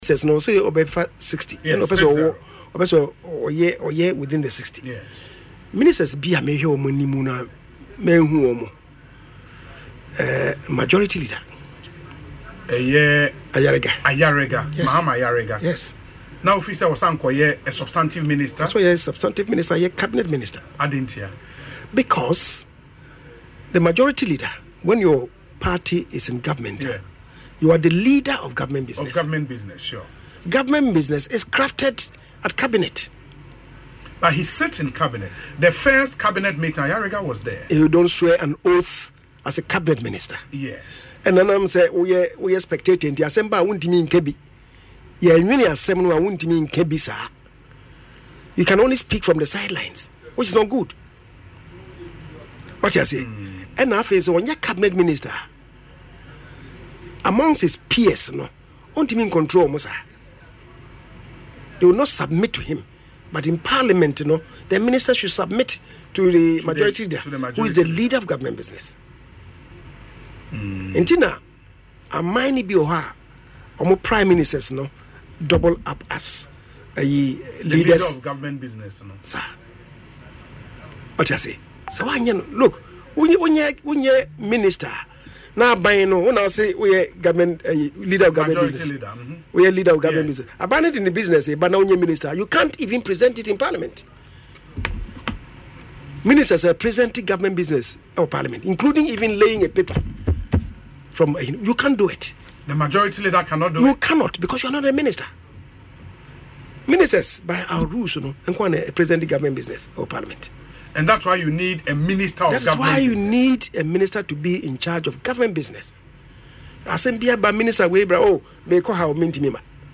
Speaking in an interview on Asempa FM’s Ekosii Sen, the former Suame MP explained that Ayariga’s role as Majority Leader automatically positions him as the leader of government business.